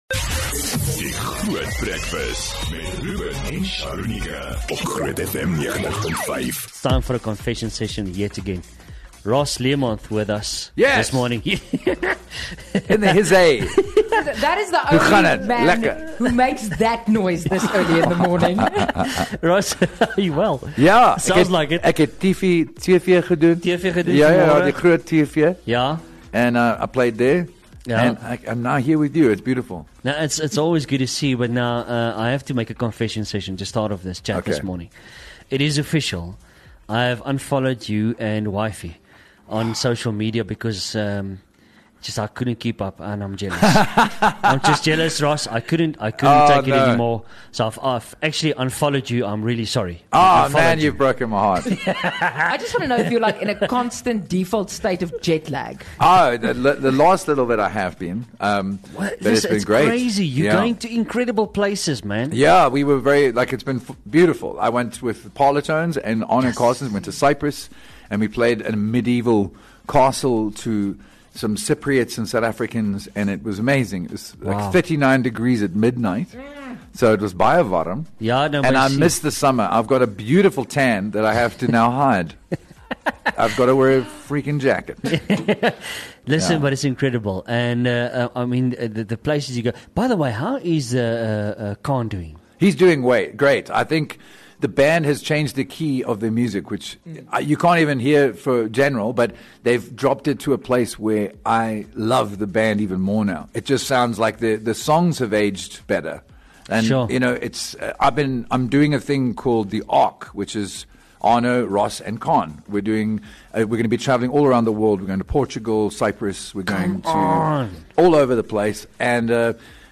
Luister gerus weer hier na die gesprek as jy dit gemis het.